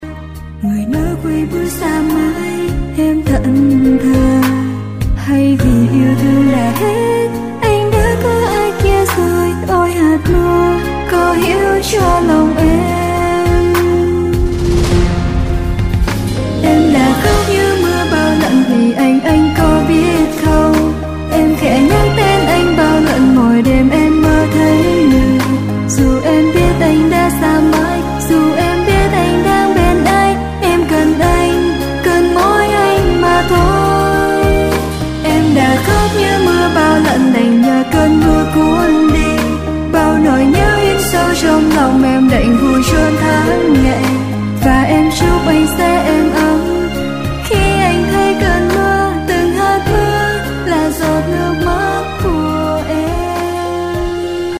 Pop/ Acoustic/ Indie